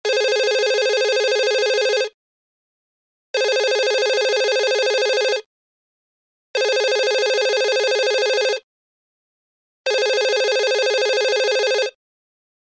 Звуки телефона TCL
Звон офисного телефона